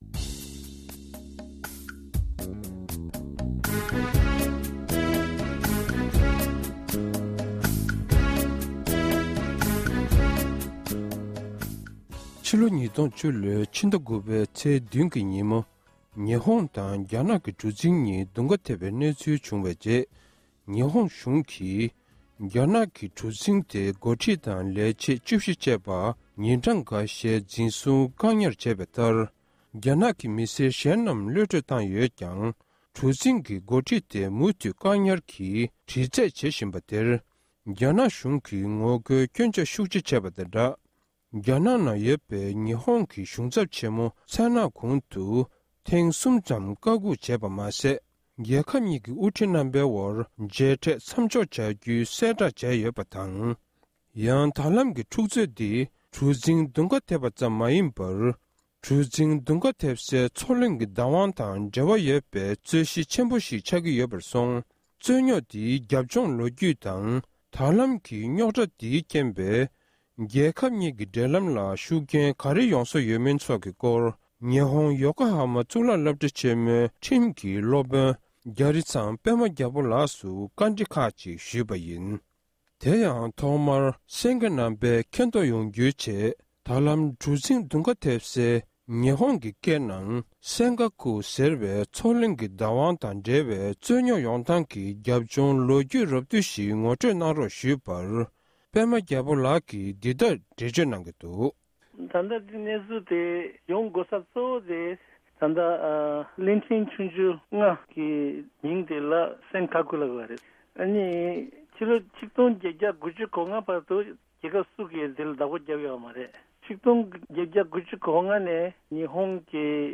སྒྲ་ལྡན་གསར་འགྱུར། སྒྲ་ཕབ་ལེན།
བཀའ་འདྲི་ཞུས་པར་གསན་རོགས༎